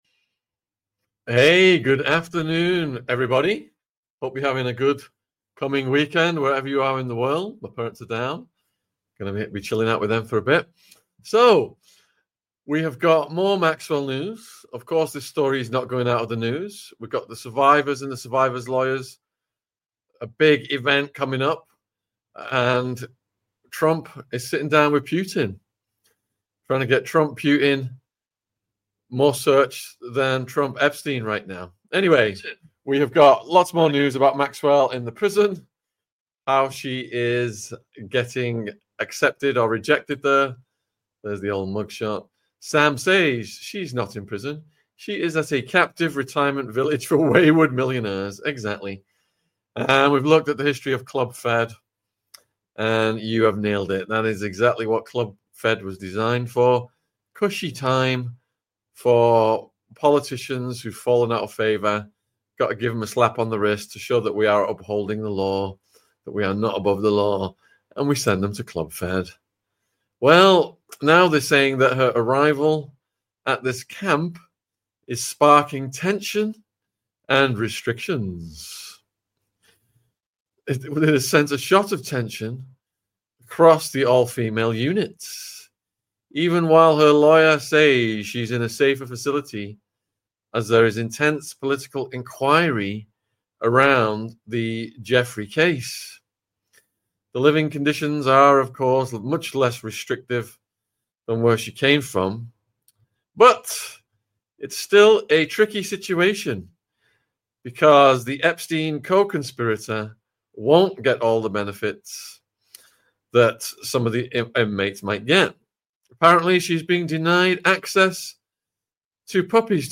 MAXWELL'S TEXAS PRISON LATEST NEWS! CALL IN SHOW 14 - Epstein Trump | AU 460